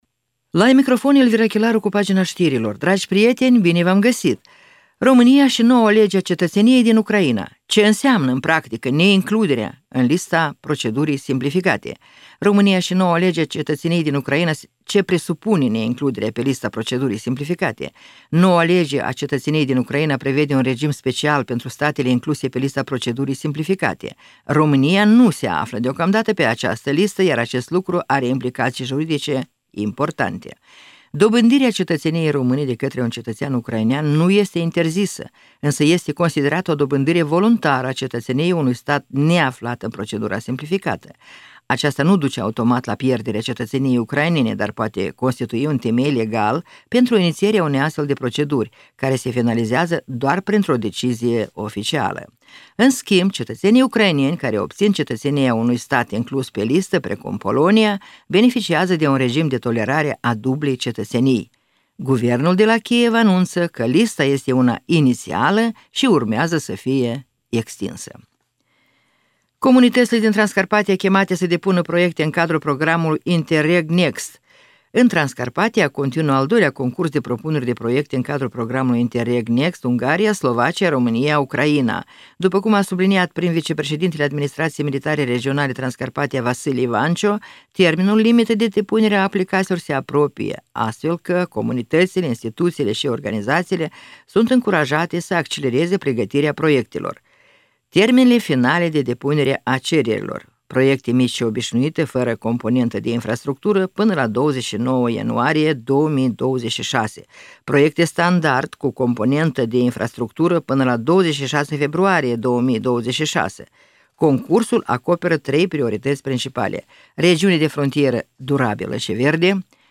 Ştiri de la Radio Ujgorod.